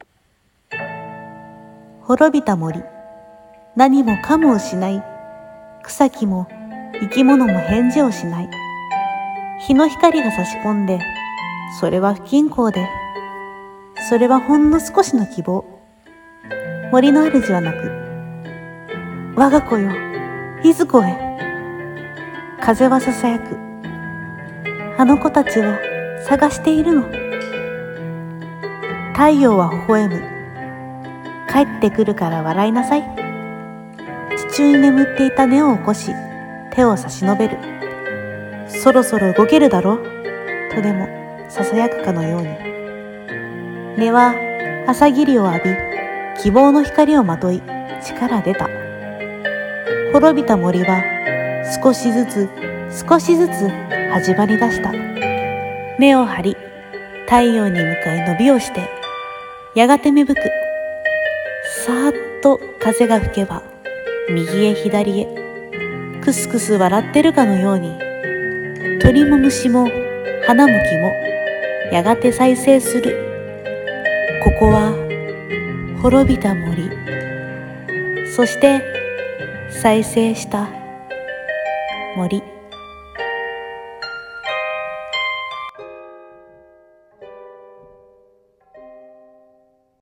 再生 【朗読台本】